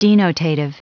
Prononciation du mot denotative en anglais (fichier audio)
denotative.wav